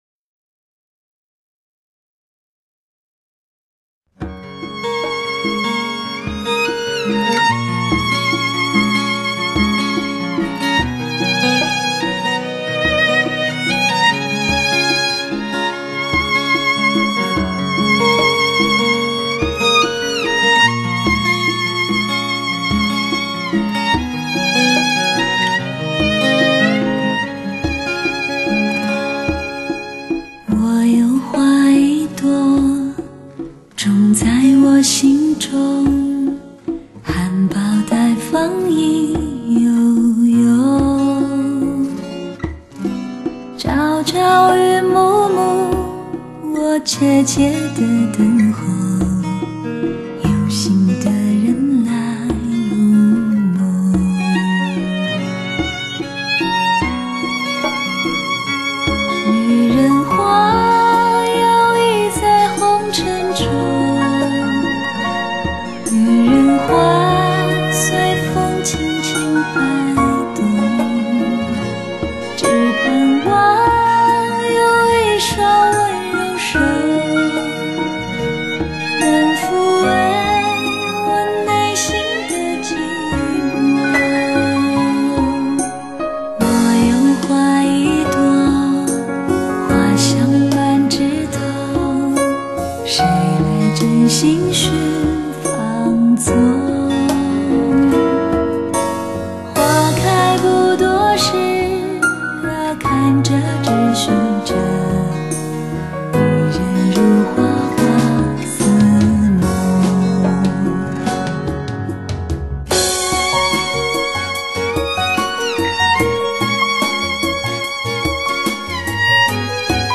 低沉的管笛 似乎在诉说这女人的脆弱和忧柔